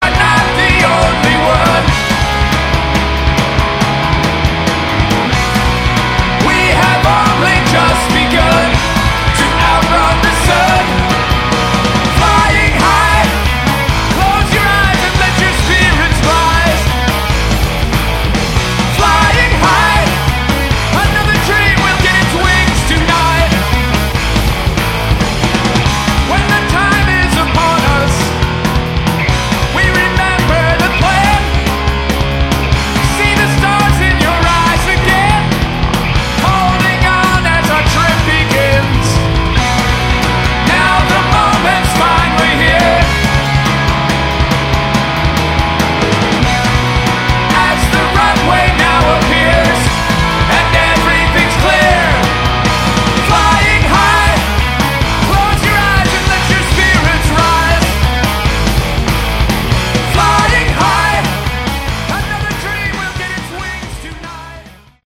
Category: Hard Rock
vocals, all instruments
Bass
Drums
Guitars